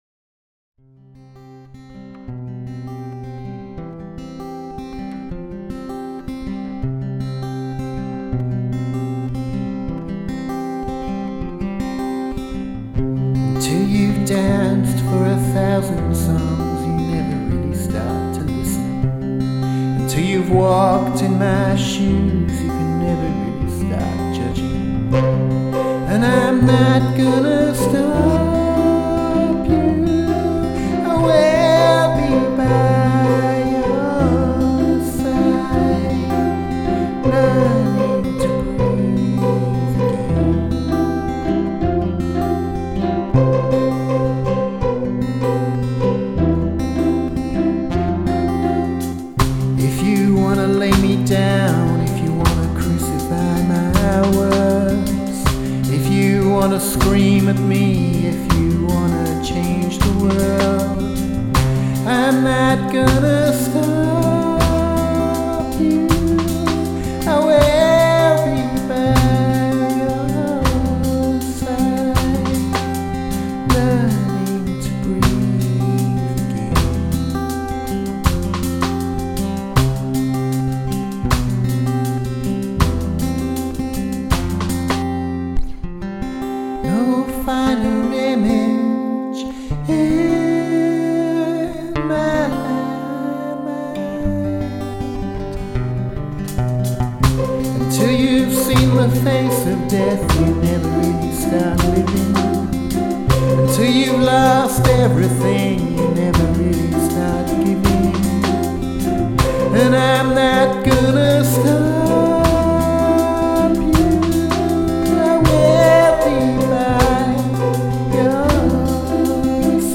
dance/electronic
Just a mellow out tune.